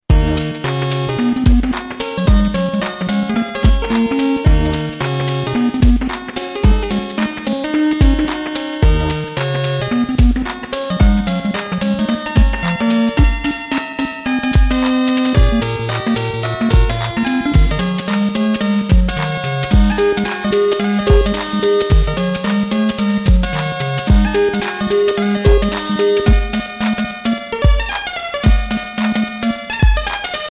Денди 8 бит